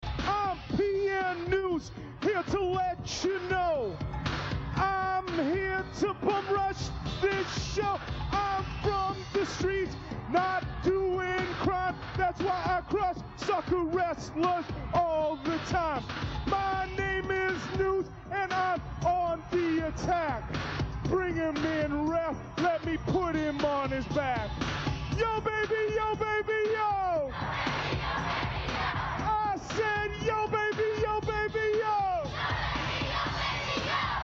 he’d come out with gold microphone in hand and regale ringsiders with a hip hop performance